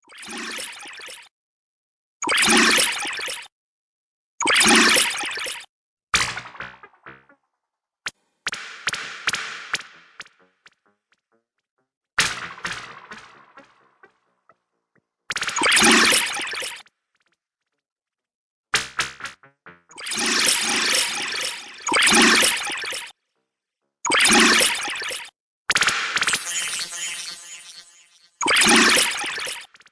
New York Bank ATM
NewYorkBankATMRingtone.mp3